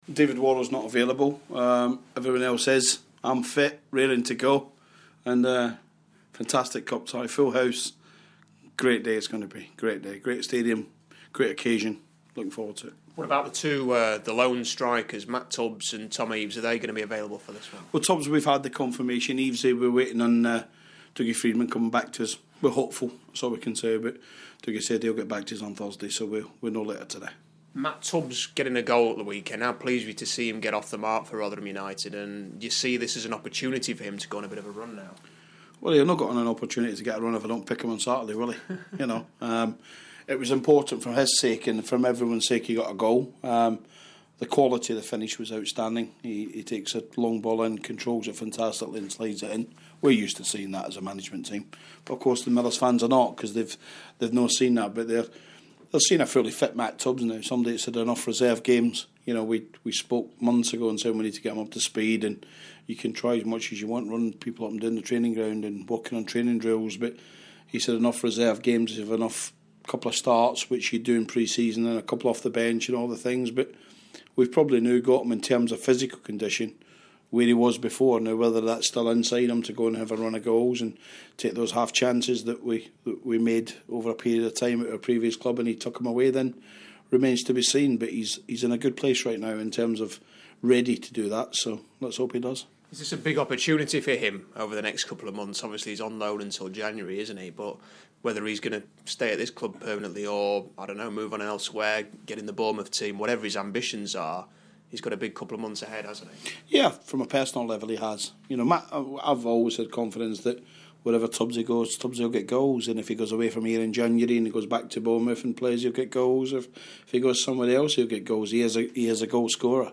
Interview: Rotherham boss Steve Evans speaking before the FA Cup tie with Bradford